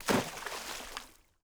SPLASH_Small_03_mono.wav